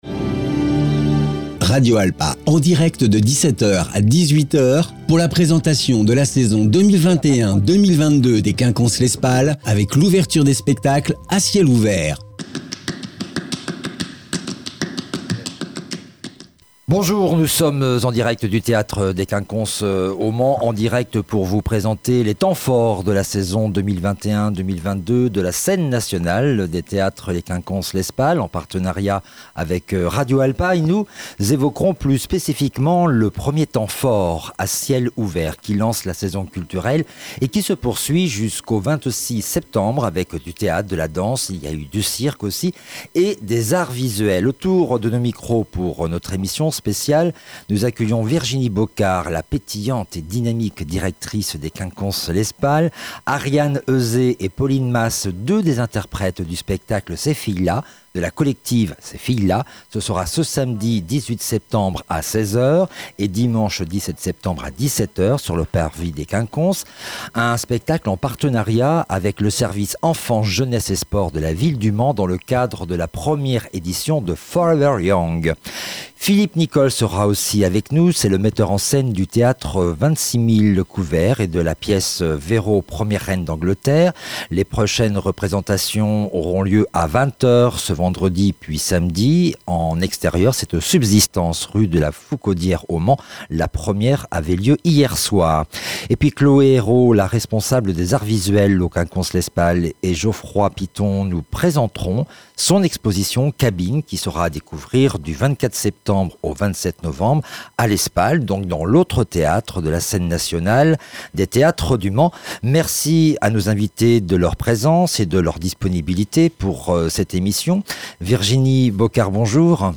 emission-a-ciel-ouvert-en-direct-des-quinconces.mp3